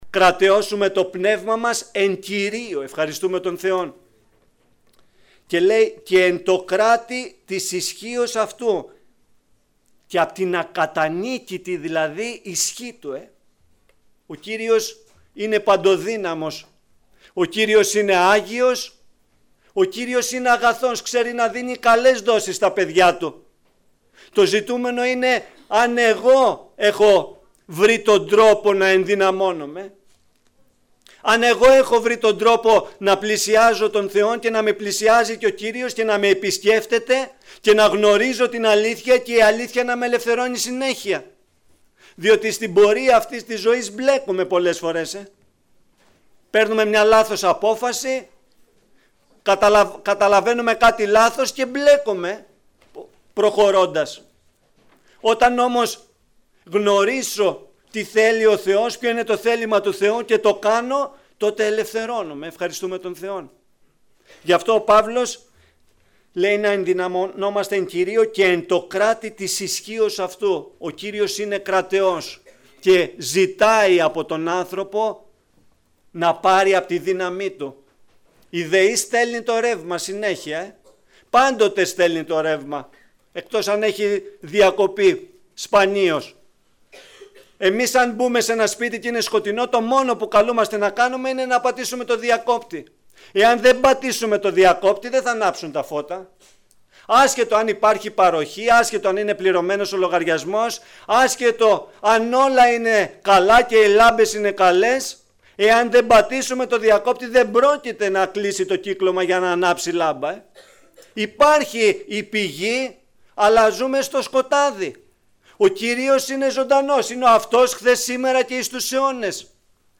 Διάφοροι Ομιλητές Λεπτομέρειες Σειρά: Κηρύγματα Ημερομηνία